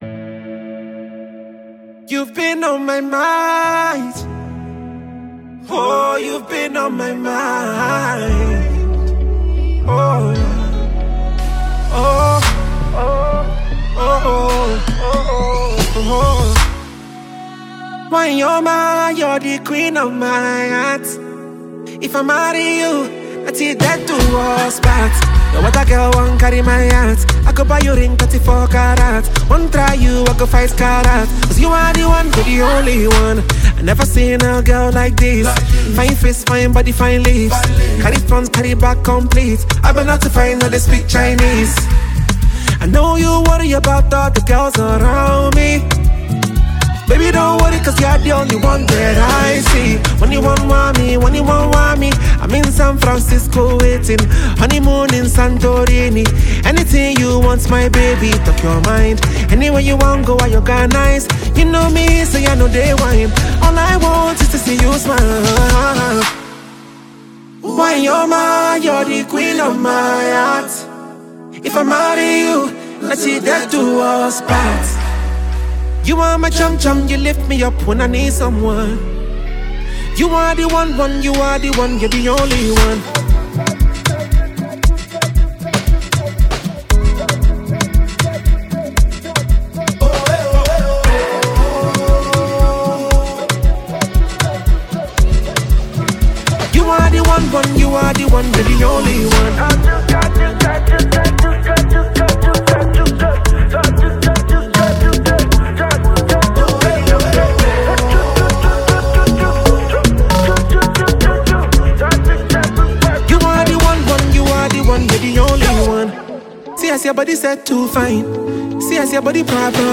Nigerian singer-songwriter and performer